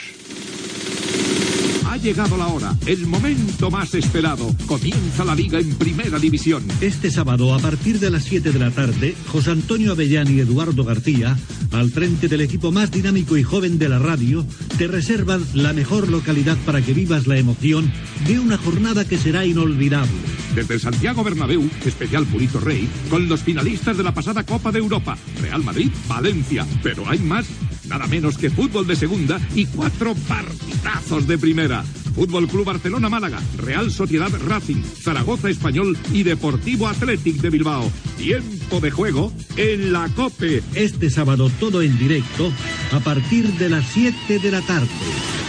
Promoció del programa del dissabte següent amb els principals partits de futbol que s'oferiran
Esportiu